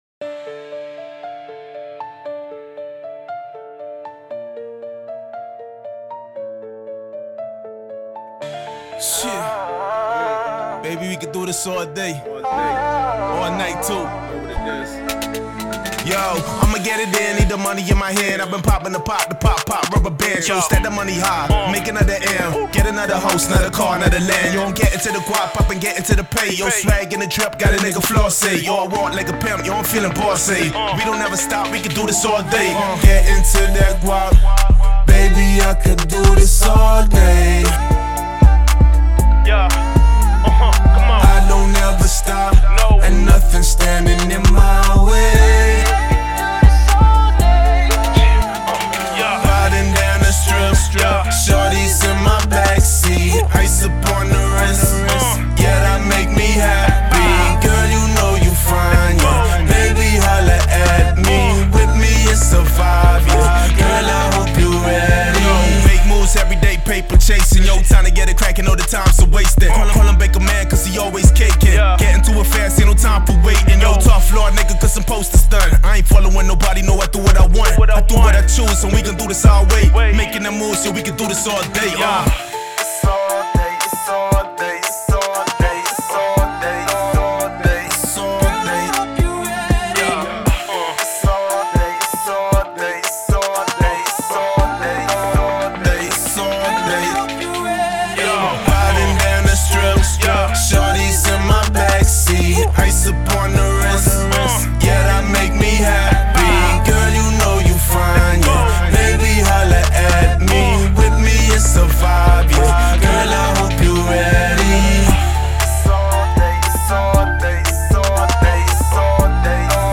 Fully mixed acapella